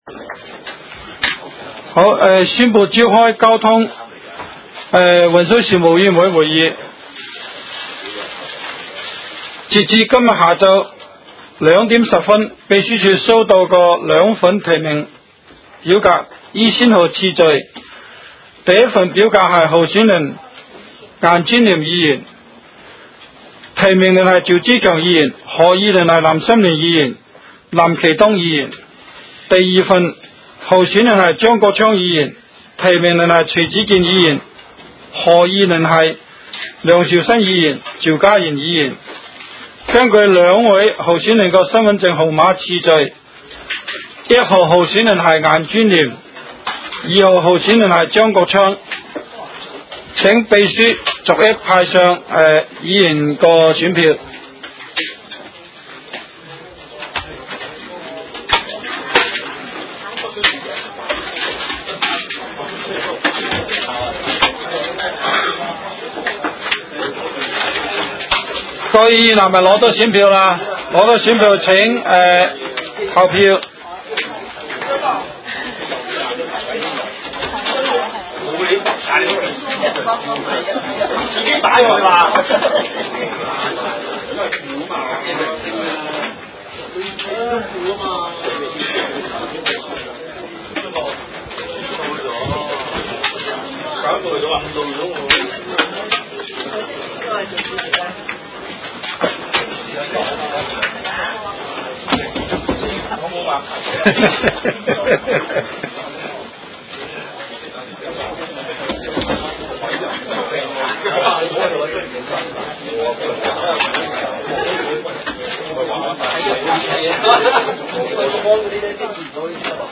委员会会议的录音记录
地点: 香港西湾河太安街29号 东区法院大楼11楼 东区区议会会议室